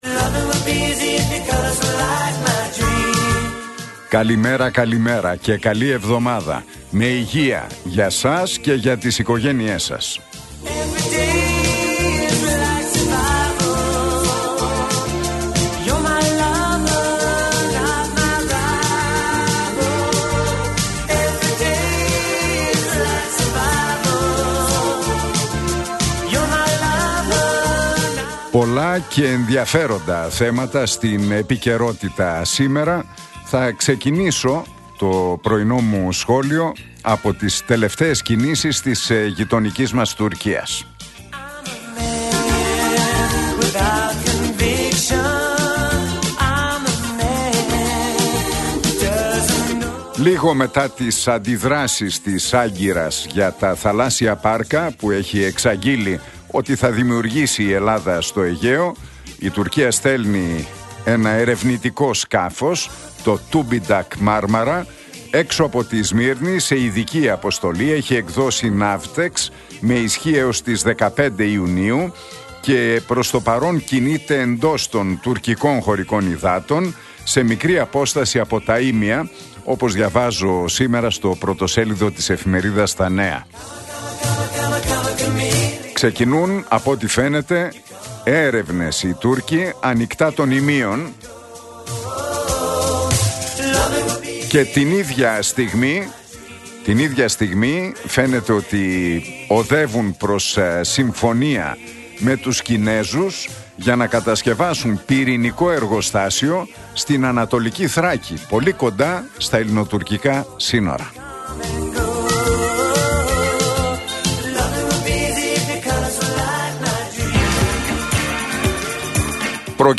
Ακούστε το σχόλιο του Νίκου Χατζηνικολάου στον RealFm 97,8, την Δευτέρα 27 Μαΐου 2024.